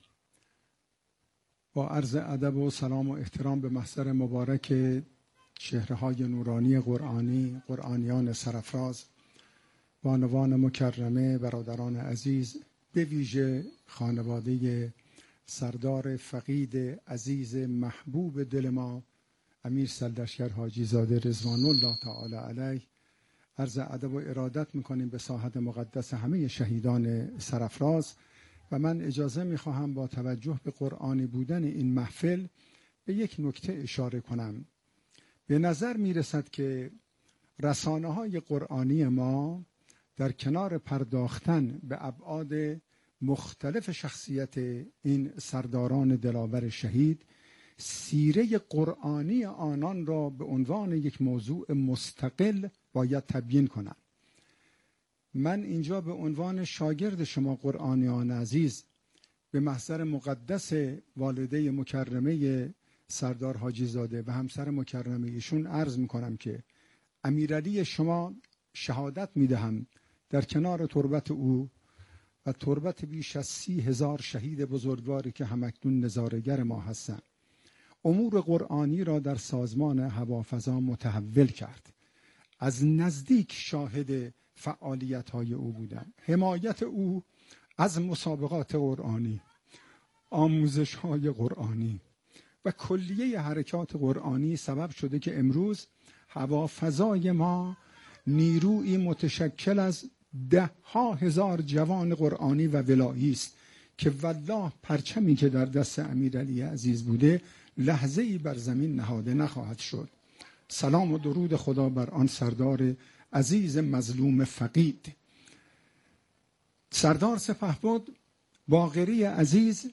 این محفل قرآنی در شهر تهران صبح امروز با حضور مدیران امور قرآنی، پیشکسوتان، استادان، فعالان، قاریان، حافظان و جمعی از مربیان و قرآن‌آموزان مؤسسات قرآنی _ مردمی از جمله اعضای مؤسسه مهد قرآن و جامعة‌القرآن و همچنین اعضای خانواده‌های شهدای جنگ ۱۲ روزه و خانواده سردار سرلشکر پاسدار شهید امیرعلی حاجی‌زاده، فرمانده فقید هوافضای سپاه پاسداران انقلاب اسلامی برگزار شد.
صوت سخنان